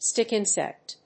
アクセントstíck ìnsect